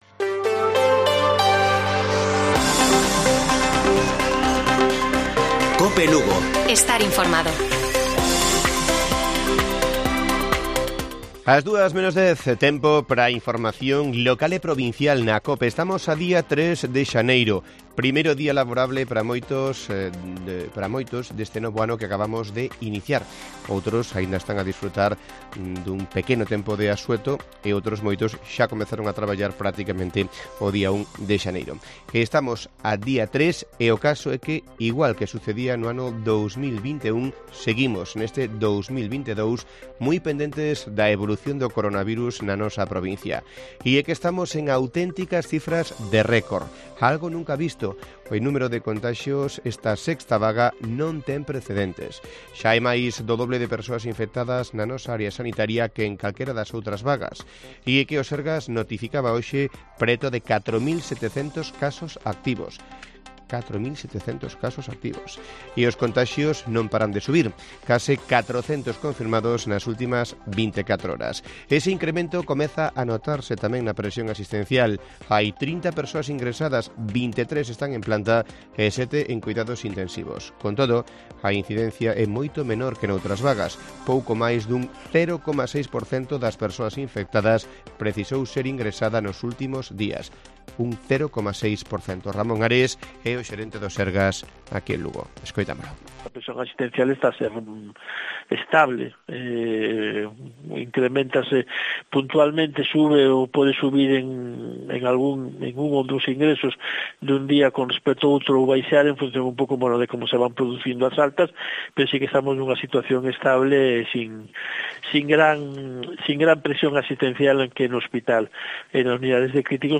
Informativo Mediodía de Cope Lugo. 03 de enero. 13:50 horas